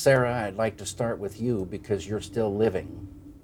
Interview by David Lynch on the Twin Peaks: The Entire Mystery Blu-ray collection
The sound of wind is heard in the background throughout the interview.